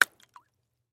Звук: бросаем шипучую таблетку (яд) в стакан